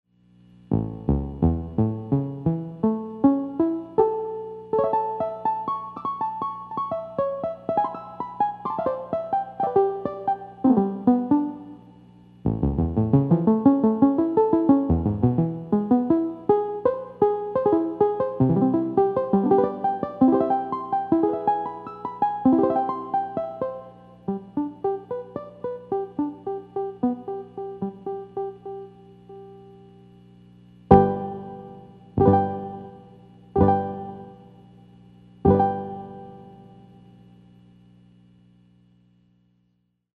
honkietonkie1solo.mp3 Same as the piano1solo.mp3, but only the Honkie Tonkie tab set to on. Also the top decay setting.
propianohonkietonkie1solo.mp3